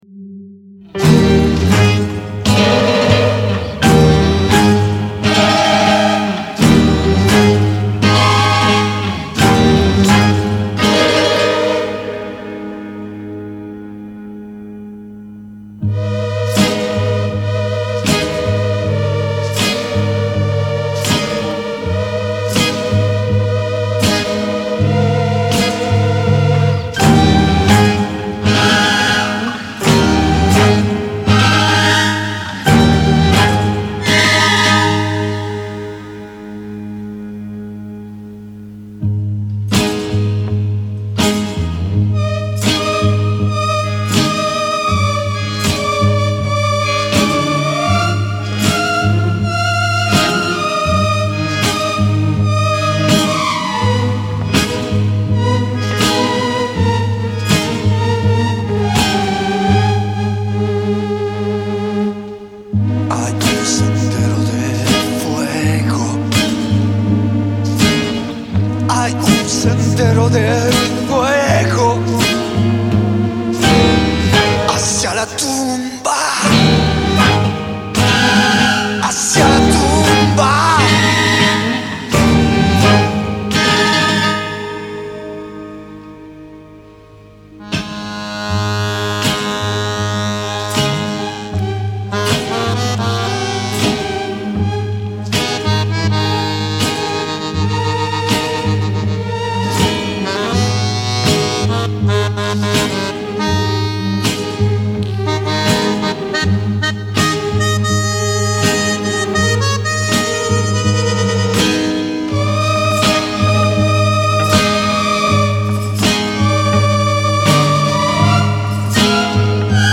Tango Satanique, magistralement déviant.